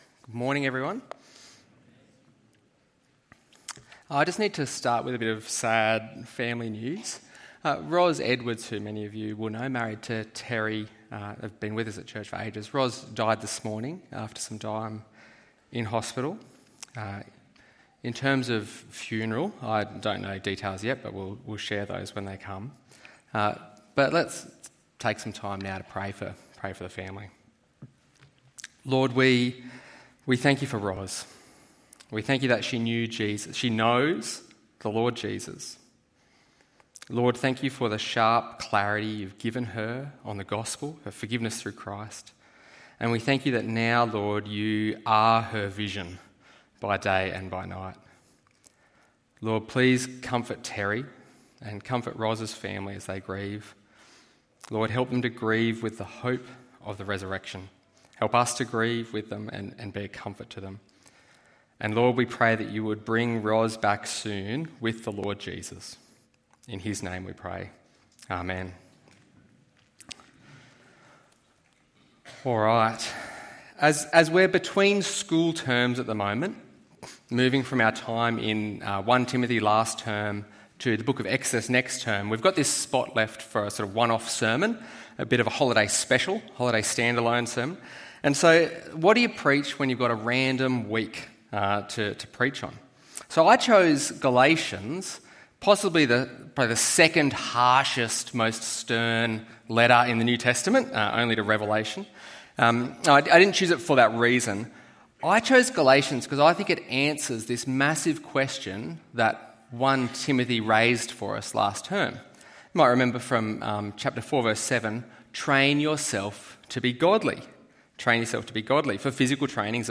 How to Train in Godliness ~ EV Church Sermons Podcast